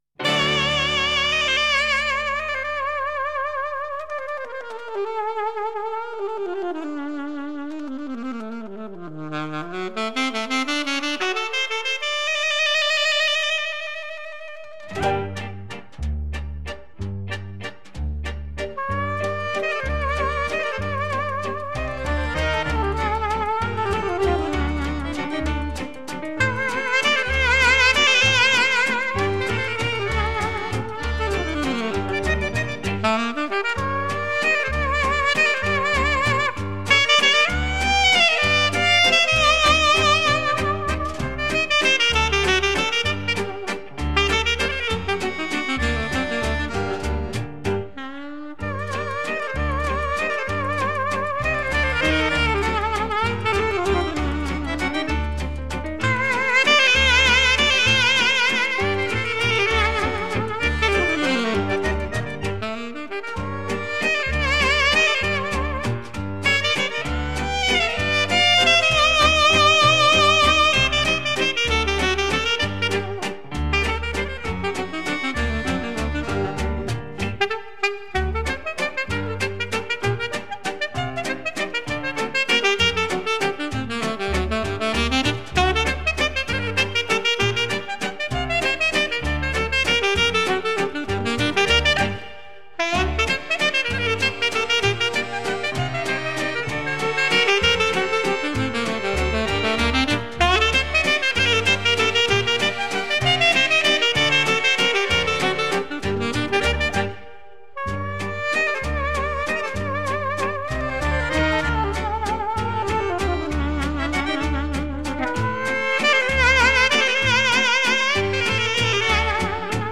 Valzer